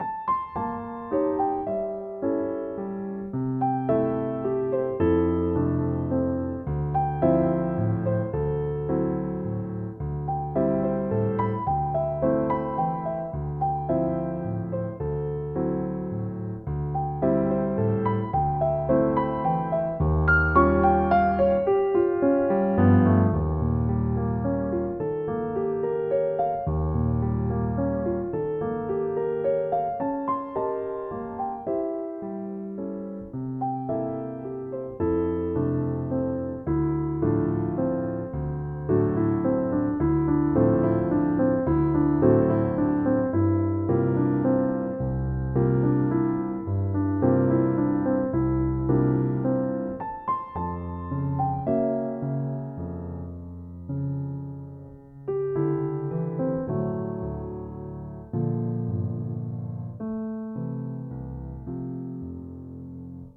シンプルな構成でメロディアスな展開が特長。